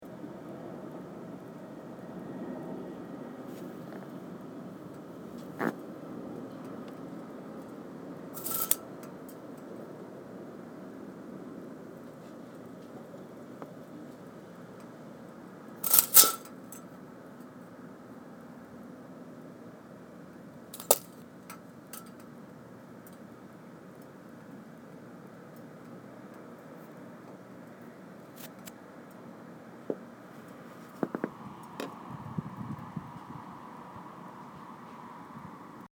Field Recording 2
Sounds: Wind blowing through the open window, blinds rattling and hitting the side of the window as the wind blows them, a car driving by in the distance.
Window.mp3